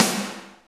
45.02 SNR.wav